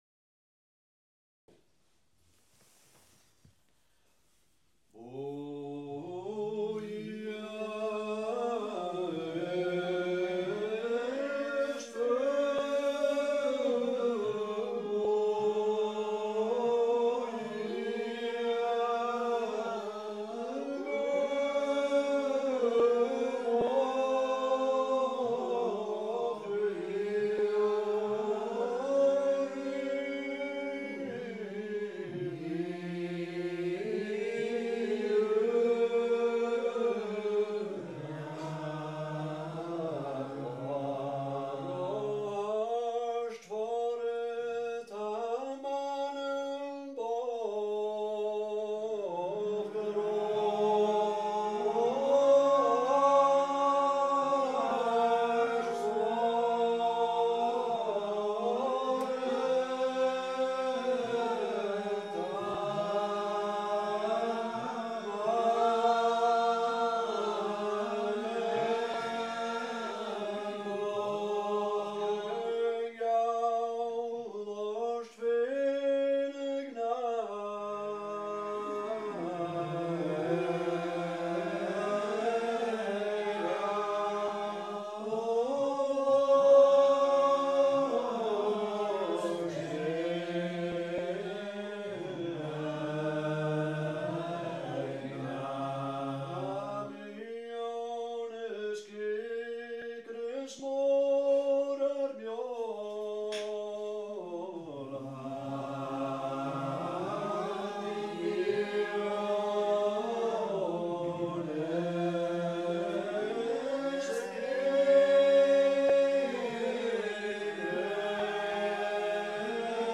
Gaelic Psalmody - class 4
A’ Seinn nan Sailm Gaelic Psalmody